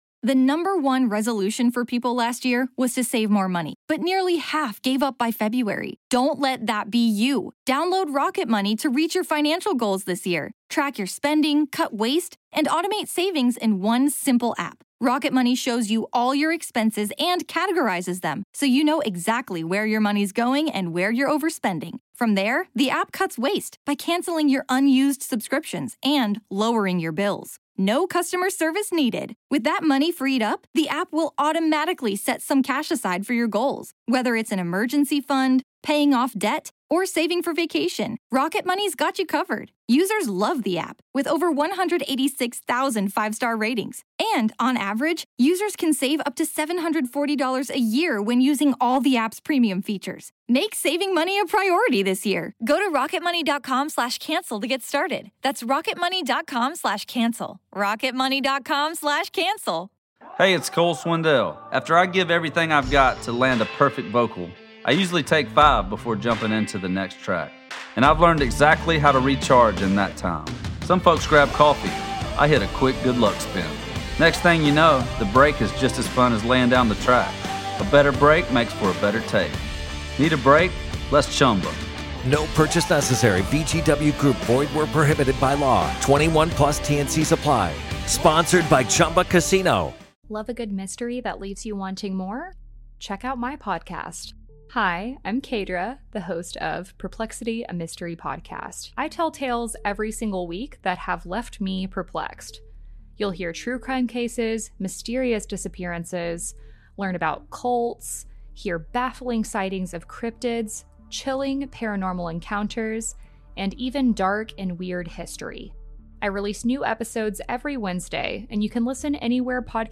Apologies for the audio quality-- we had some unforeseen technical difficulties in this episode.